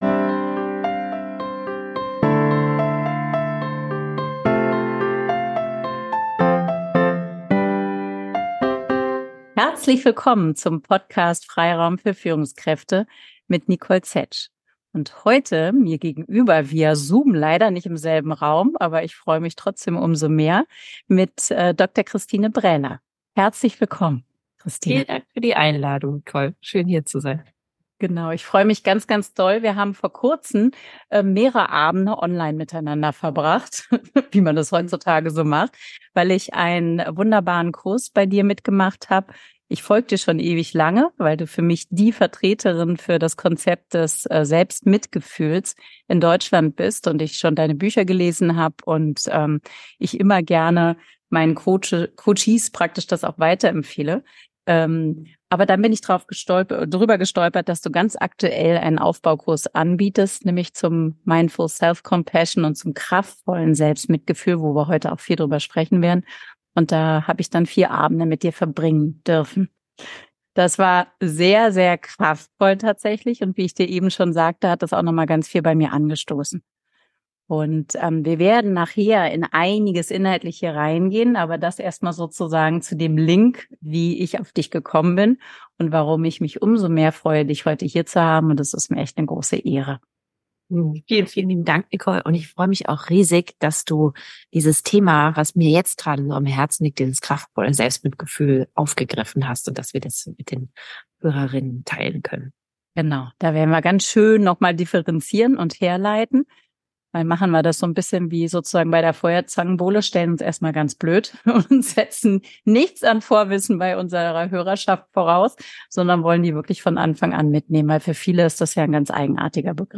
#48 Kraftvolles Selbstmitgefühl - Interview